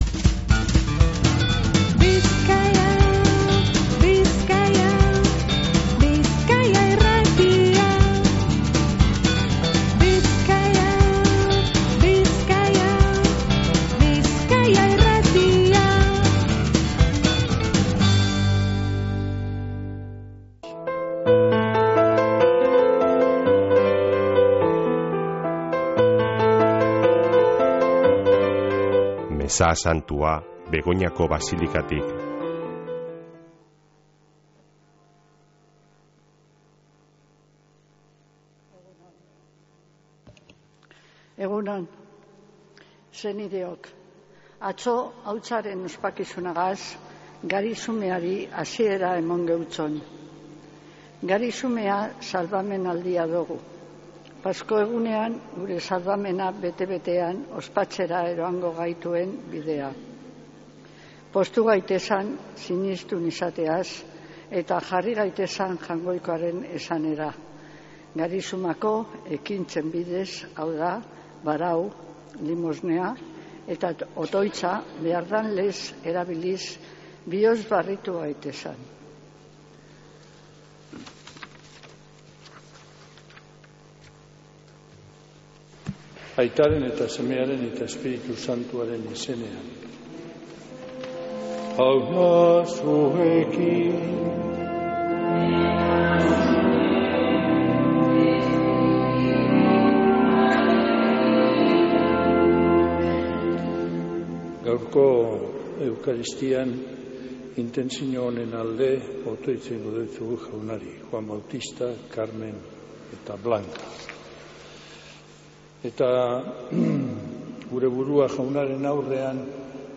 Mezea Begoñako Basilikatik | Bizkaia Irratia
Mezea (25-03-06)